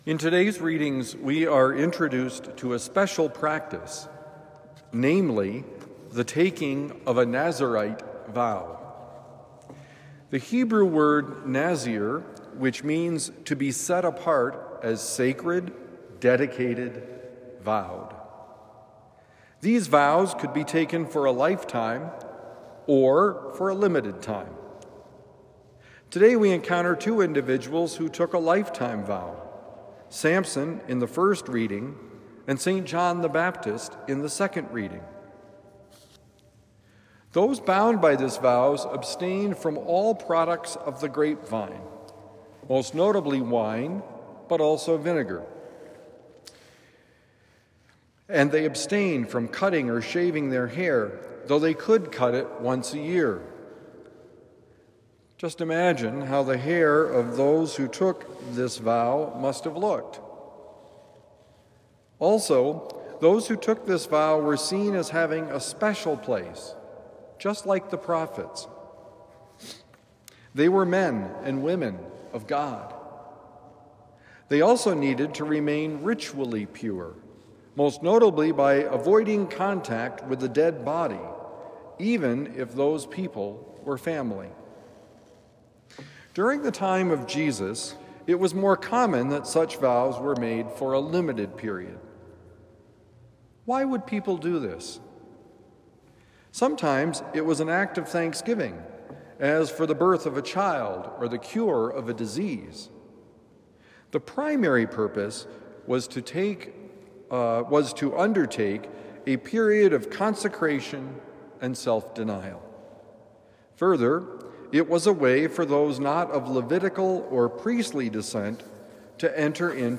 Set Apart by Baptism: Homily for Thursday December 19, 2024